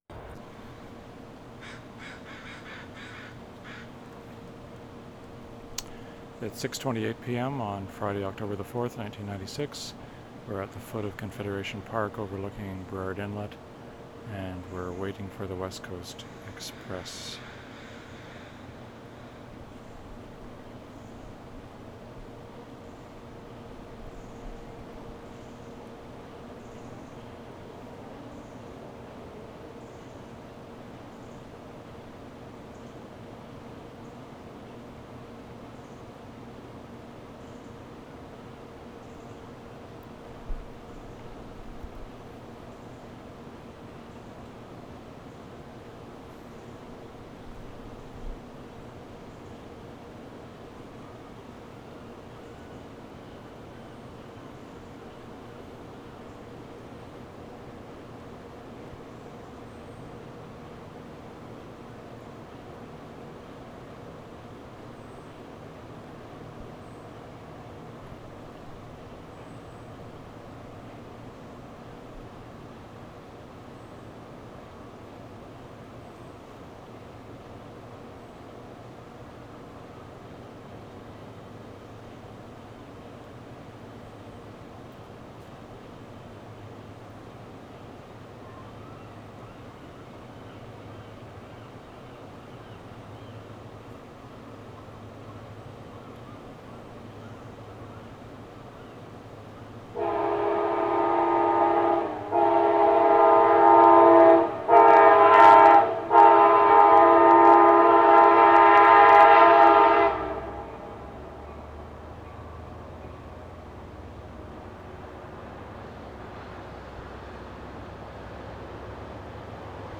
West Coast Express close-up 2:45
5. At Confederation Park just above the tracks, tape ID. 1:40 train whistle with distortion in right channel. Train passes with dynamic L-R pan.